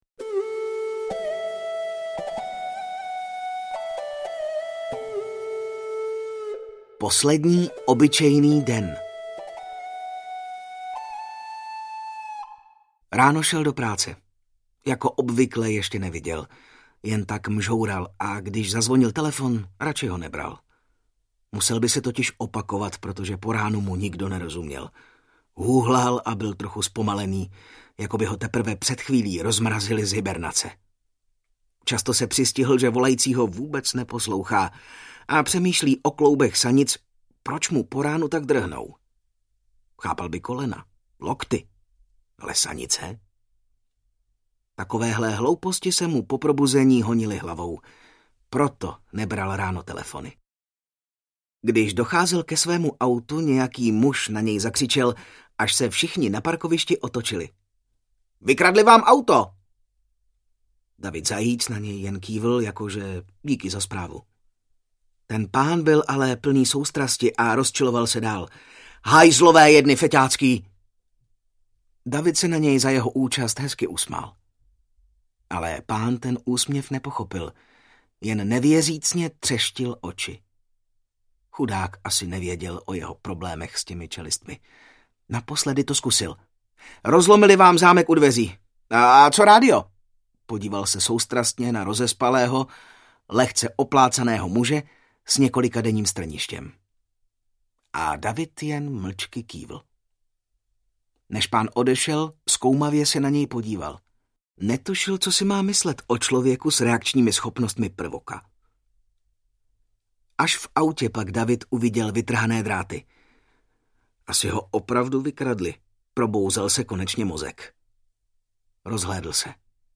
Ukázka z knihy
prsaty-muz-a-zlodej-pribehu-audiokniha